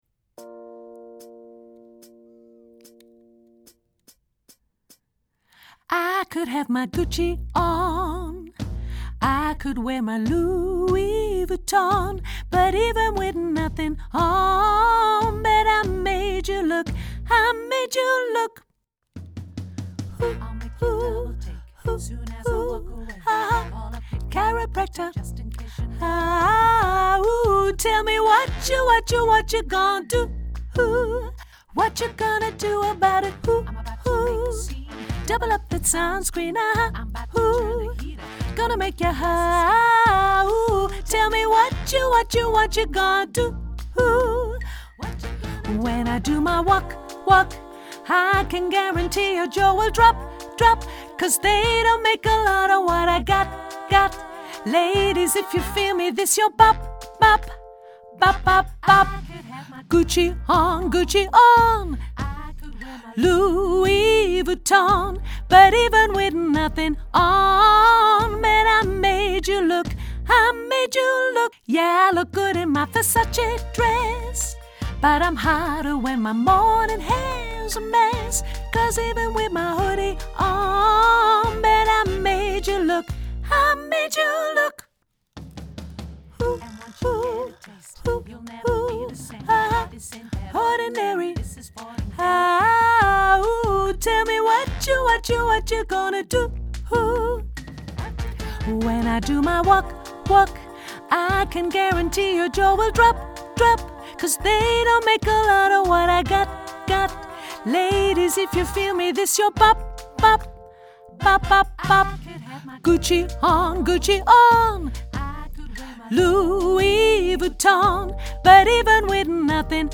hoog sopraan